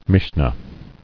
[Mish·nah]